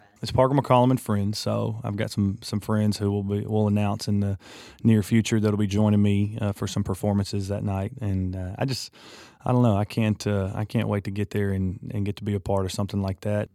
Audio / Parker McCollum talks about his upcoming ACM Lifting Lives Show, Parker McCollum and Friends.